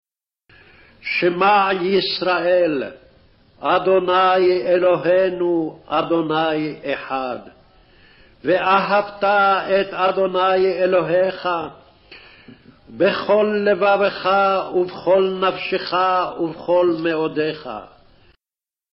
Slower Speed
Deut_6_4-5_slow.mp3